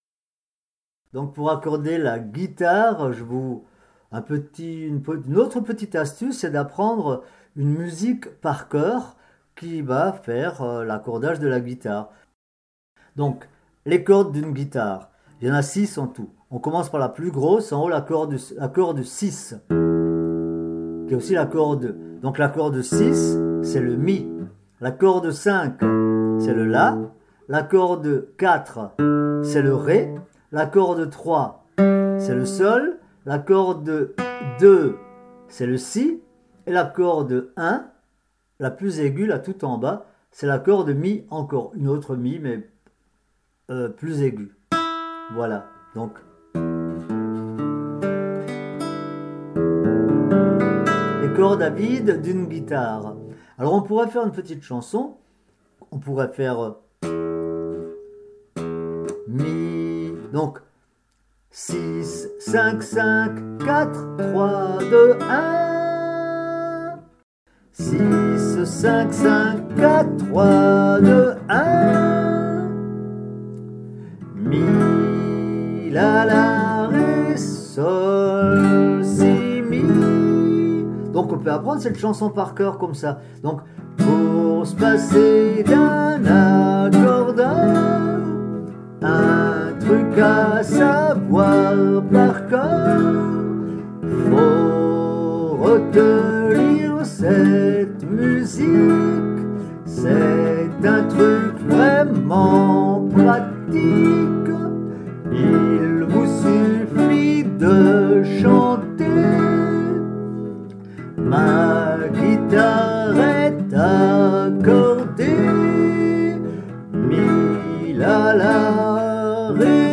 Cours de guitare à l'université populaire de Mulhouse
Accorder sa guitare en chantant (mp3)
accorder-sa-guitare-en-chantant.mp3